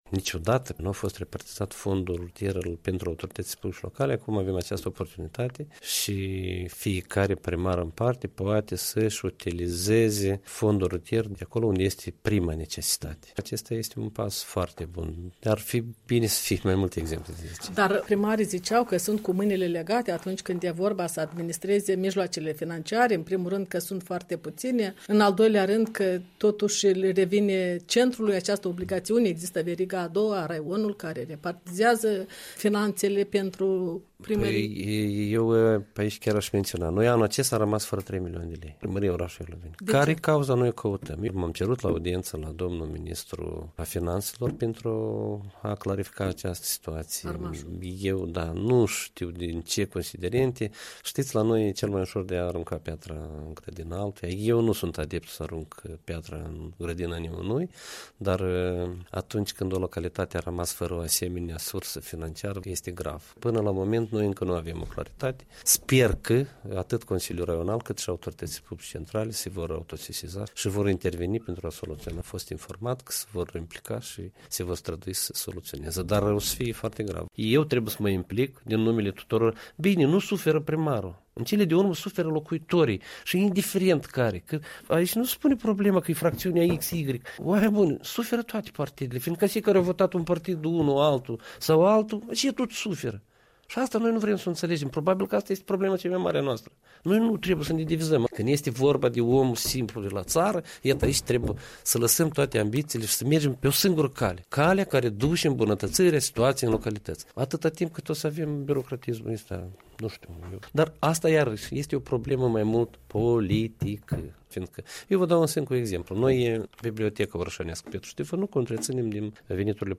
Interviu cu Sergiu Armașu